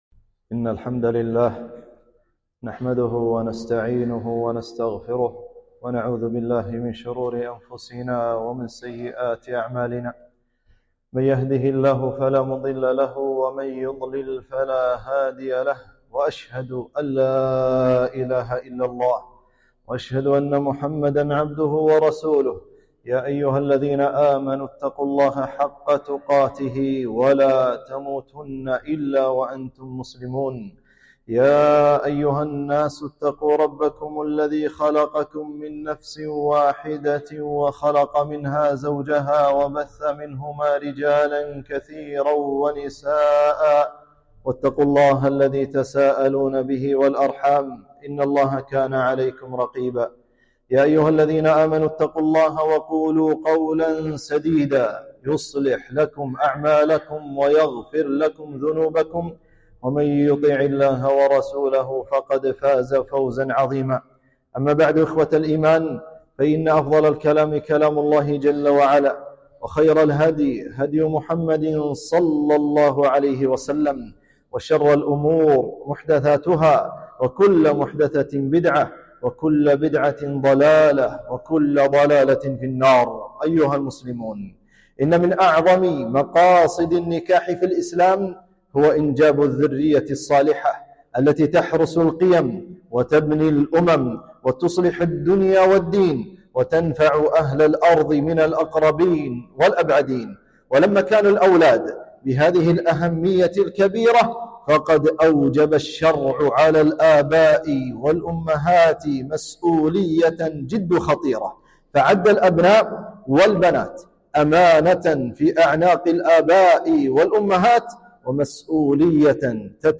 خطبة - حماية الأبناء من أفكار داعش